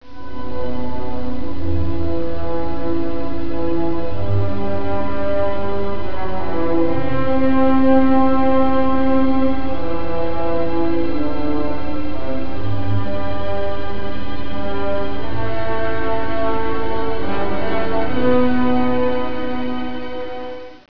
And now the Stuffed Animal Symphony Orchestra brings you an instrumental snippet of one of our favorite selections, "Wotan's Farewell and Magic Fire Music" (225k .wav file)....